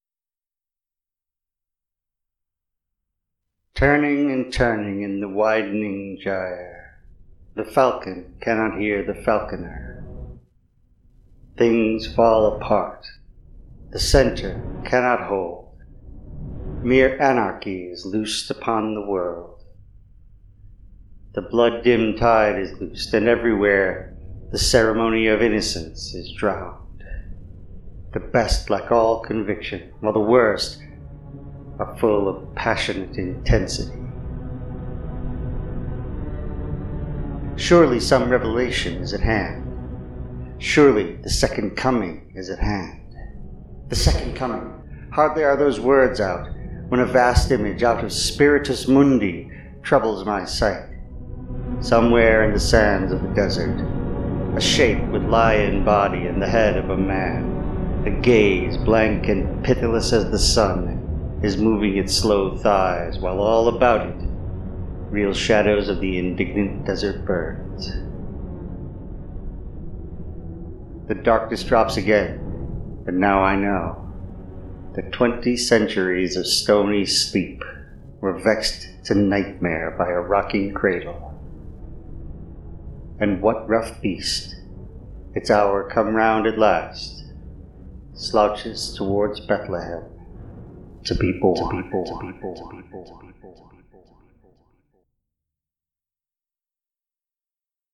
While on my way in to work today, I just started reciting this and one other poem to myself, and then I decided I’d just record a recitation and add a bit of music/special effects to this, and to the other poem, which will follow shortly.